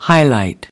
highlight /10/ /’haɪ.laɪt/ /’haɪ.ˌlaɪt/